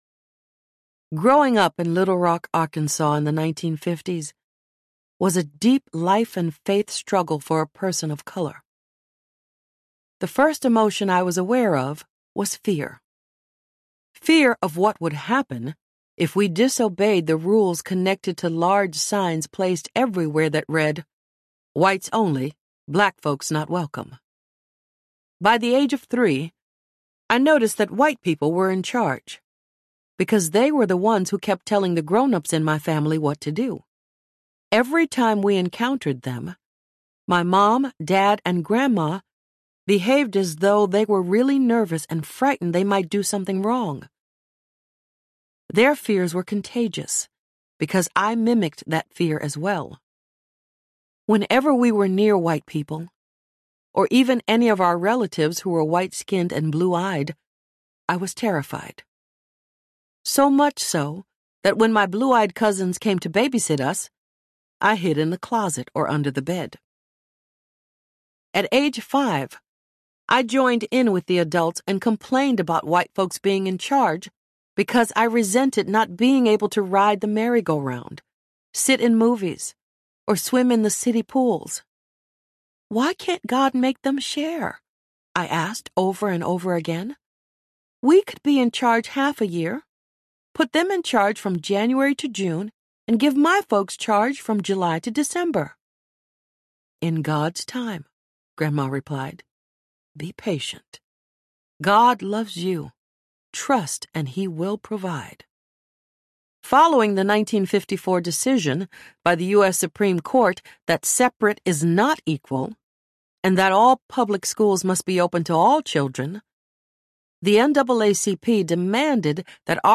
I Will Not Fear Audiobook